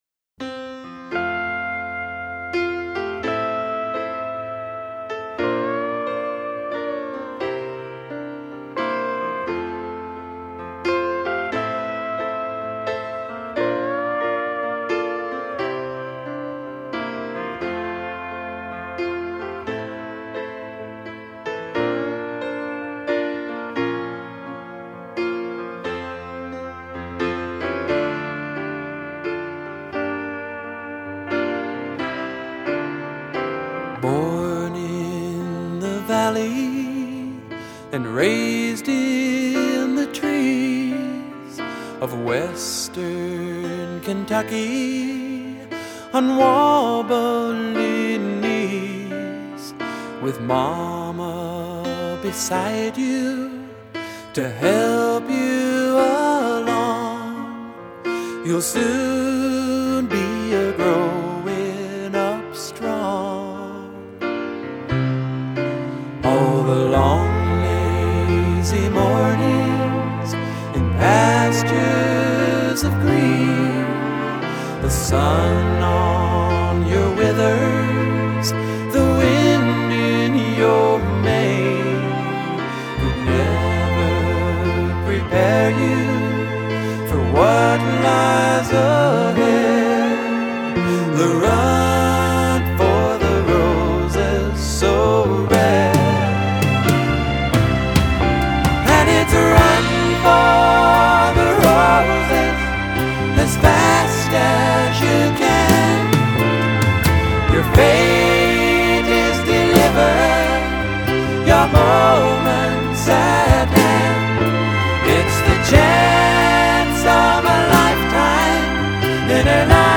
★ 美國最具代表性創作者之一，以輕柔歌聲、動人歌詞與吉他走紅70年代的民謠詩人！